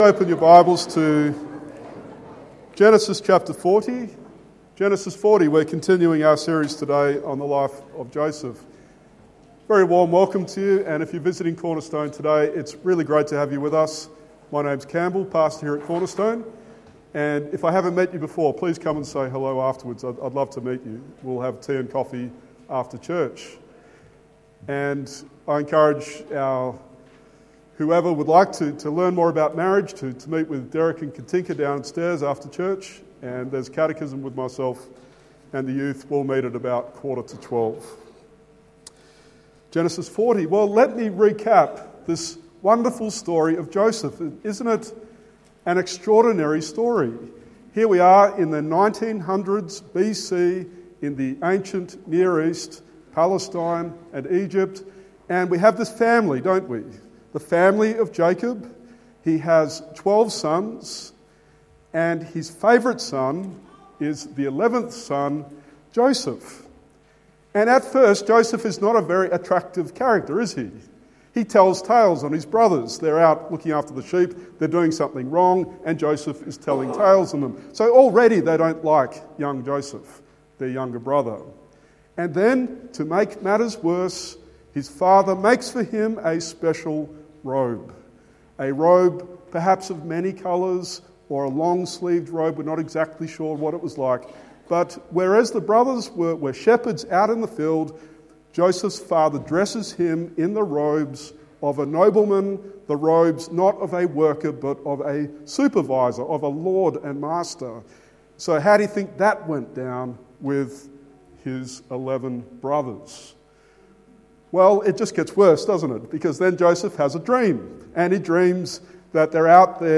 Genesis 40:1-21 Sermon